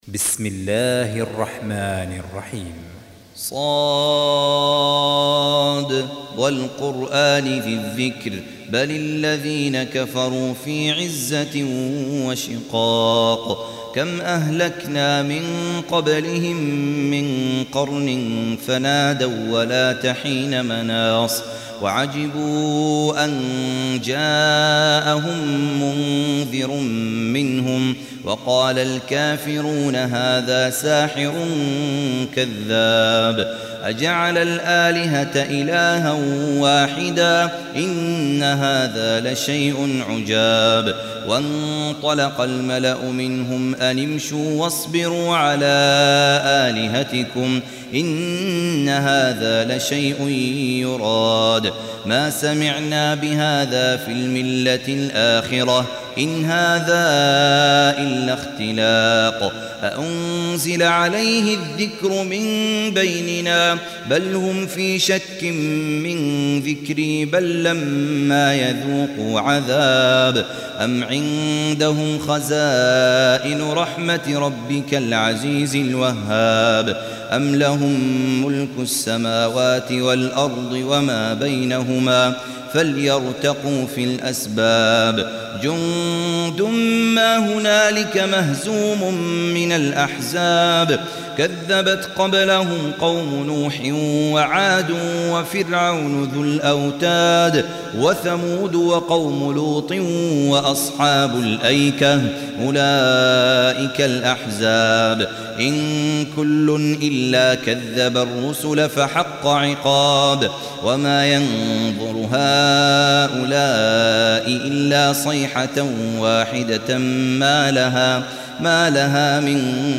Surah Repeating تكرار السورة Download Surah حمّل السورة Reciting Murattalah Audio for 38. Surah S�d. سورة ص N.B *Surah Includes Al-Basmalah Reciters Sequents تتابع التلاوات Reciters Repeats تكرار التلاوات